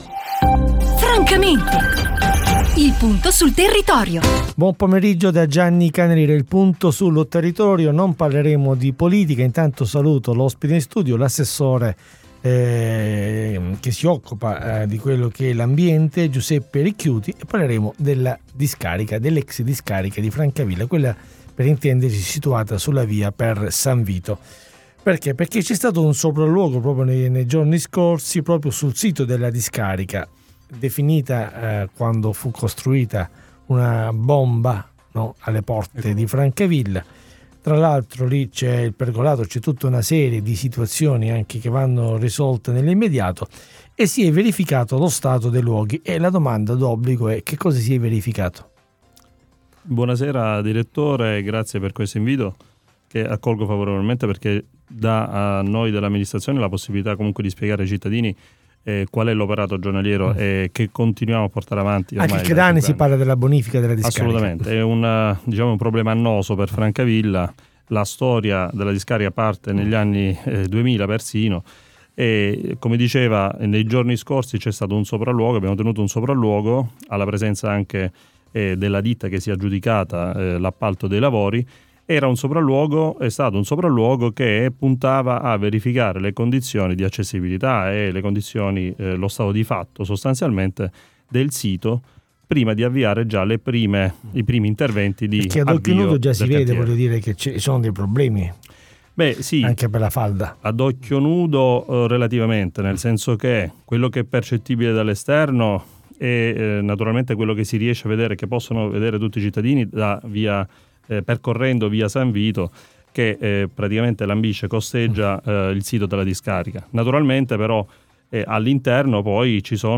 Tema della puntata: "Situazione ex discarica". Ospite in studio l'assessore all'ambiente del Comune di Francavilla Fontana Giuseppe Ricchiuti ...